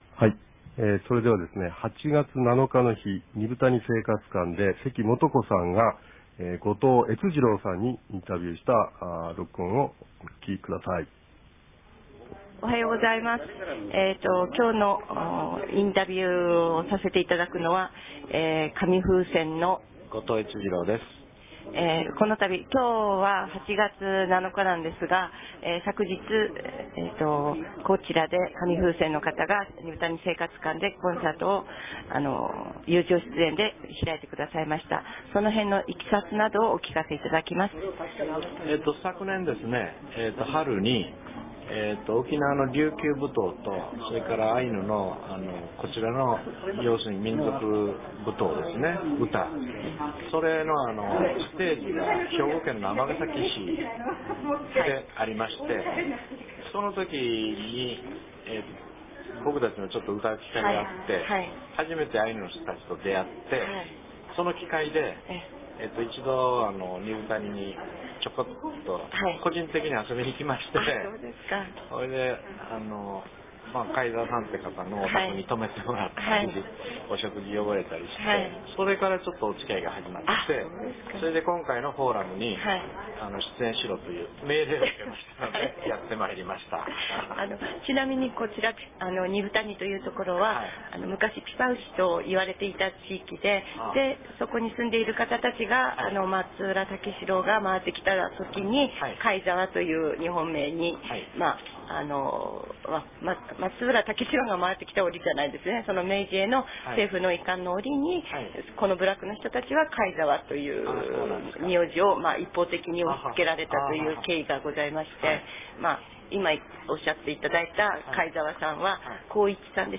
■二風谷のスタジオから「わいわいがやがや」